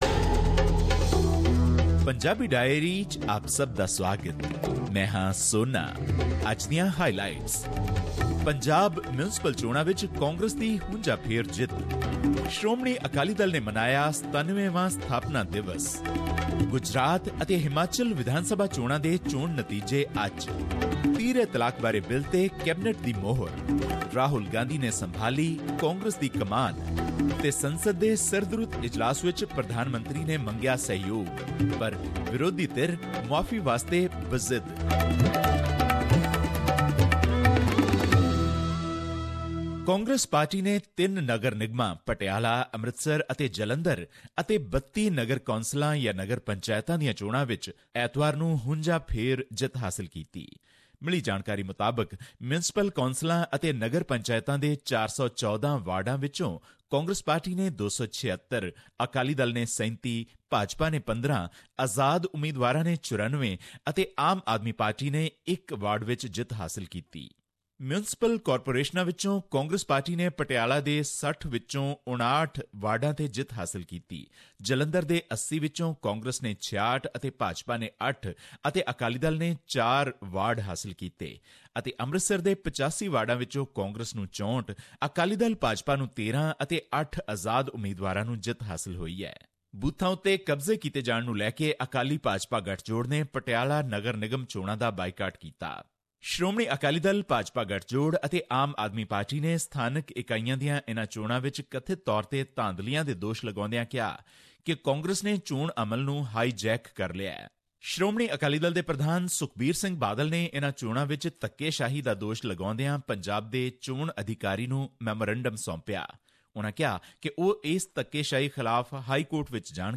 This report was presented on SBS Punjabi program on Dec 18, 2017, which touched upon issues of Punjabi and national significance in India. The report covers the results of Punjab civic polls, which shows a sweeping victory for the ruling Congress party who won 66 of 80 wards in Jalandhar, 59 of 60 in Patiala, and 64 of 85 in Amritsar.